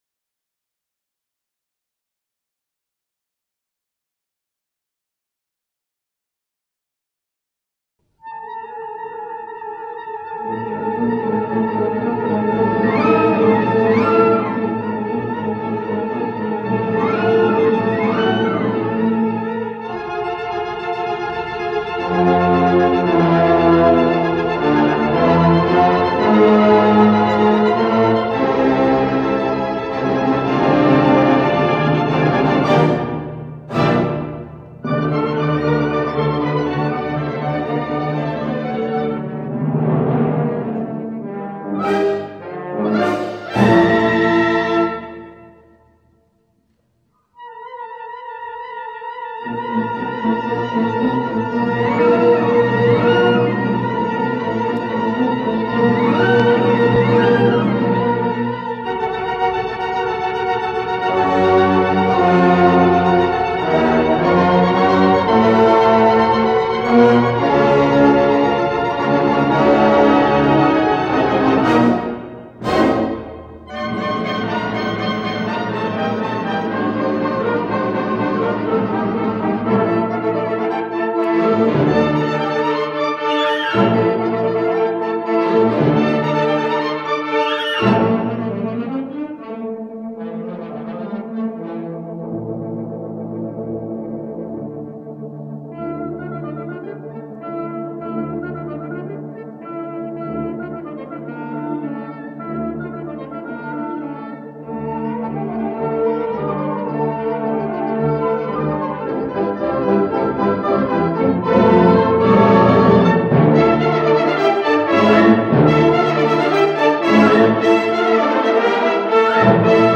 Tempesta_base_musicale.mp3